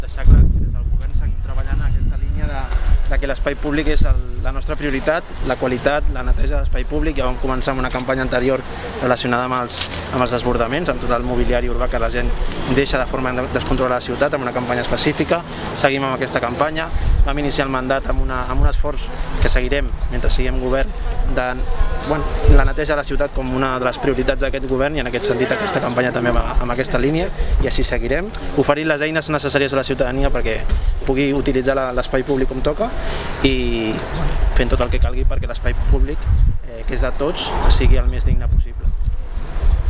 -declaracions de Carlos Cordón